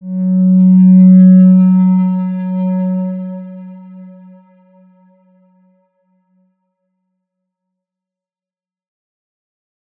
X_Windwistle-F#2-pp.wav